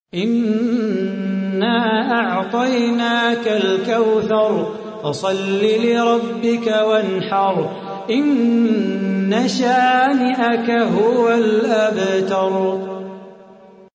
تحميل : 108. سورة الكوثر / القارئ صلاح بو خاطر / القرآن الكريم / موقع يا حسين